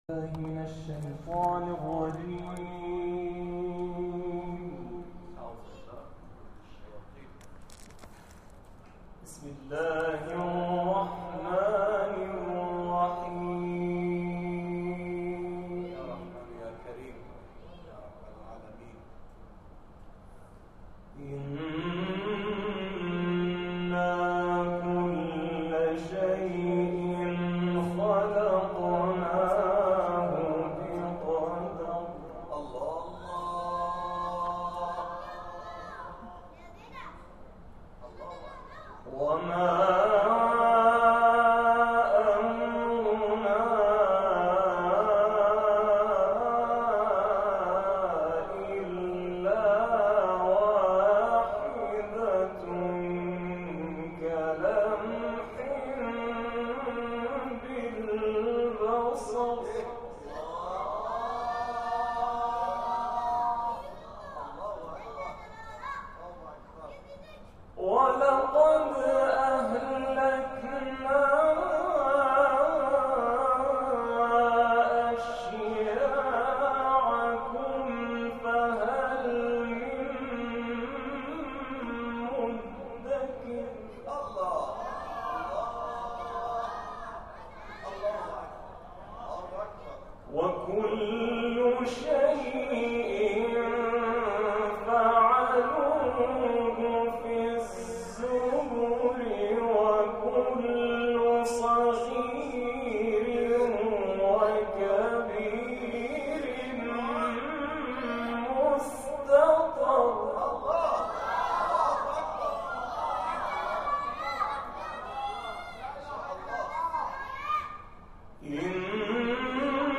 ویژه ماه مبارک رمضان از ساعت 22 الی 1 بامداد در مسجد شهدا، واقع در تهران، اتوبان شهید محلاتی برگزار شد.
جمع‌خوانی شد.